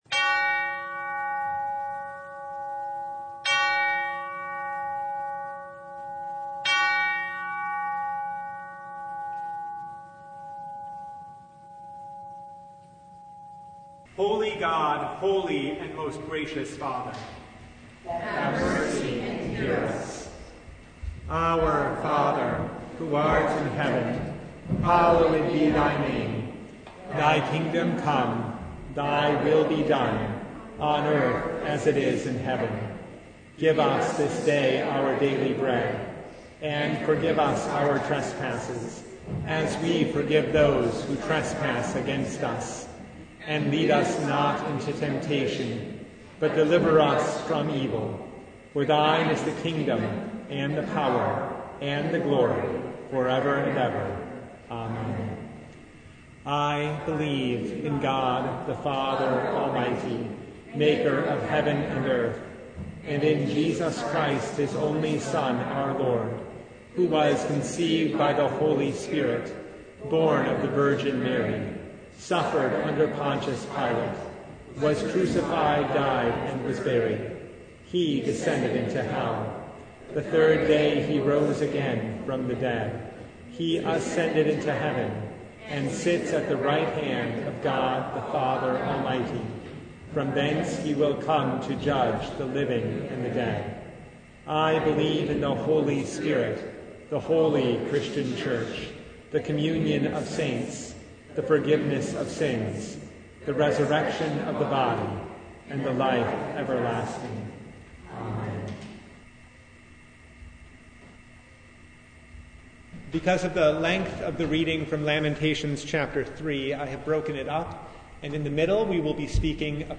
Passage: Lamentations 3, Mark 15:16-20, 27-34 Service Type: Lent Midweek Noon
Full Service